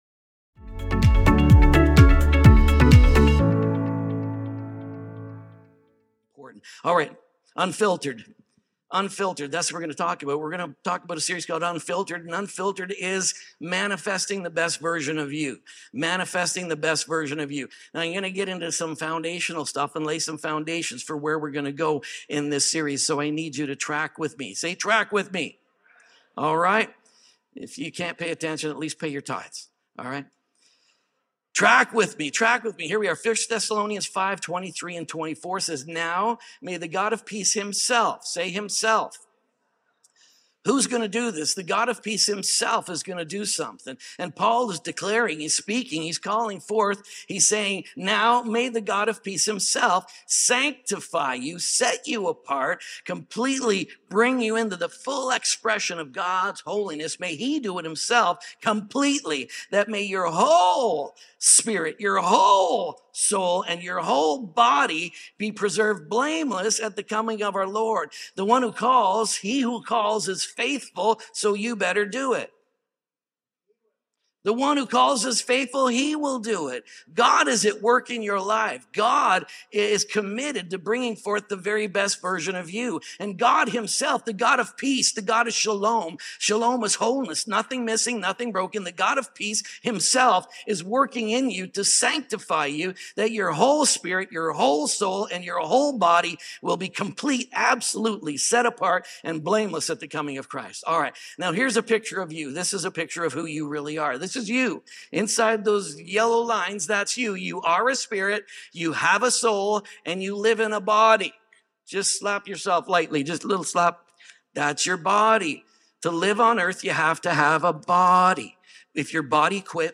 ReFathered | UNFILTERED | SERMON ONLY 2.mp3